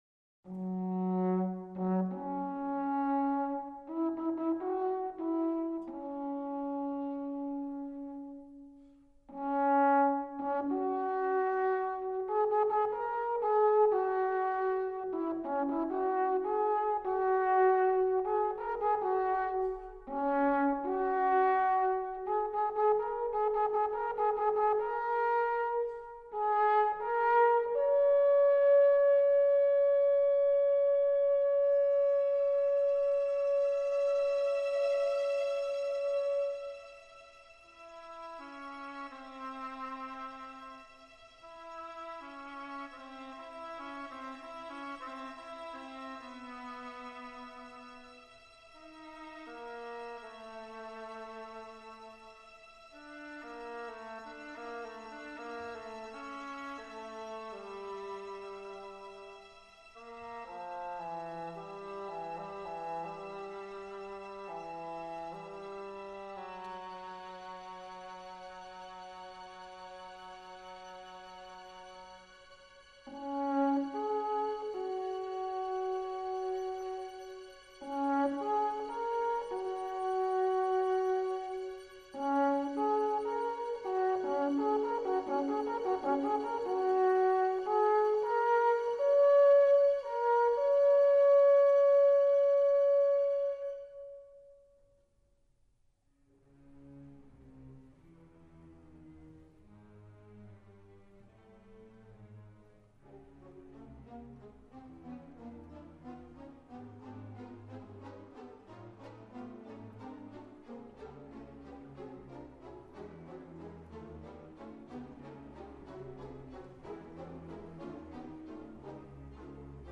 für Alphorn in F und Klavier Schwierigkeit